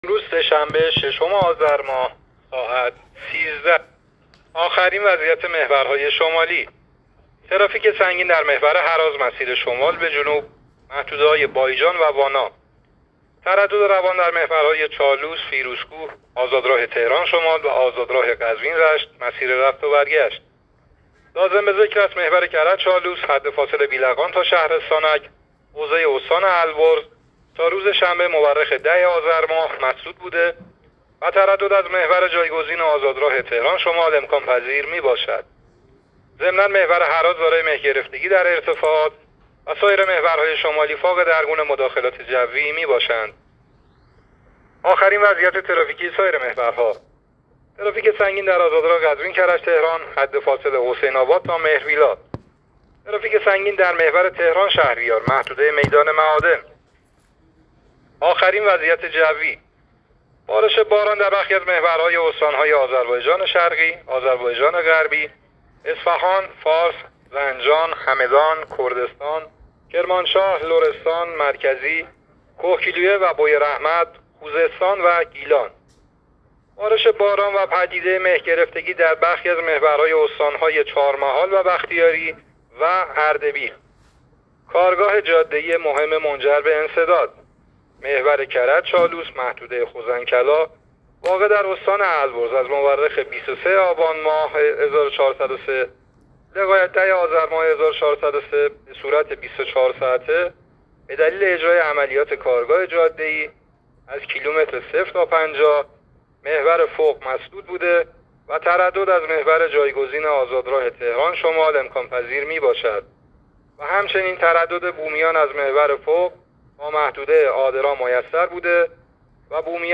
گزارش رادیو اینترنتی از آخرین وضعیت ترافیکی جاده‌ها تا ساعت ۱۳ ششم مهر؛